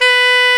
SAX TENORM0I.wav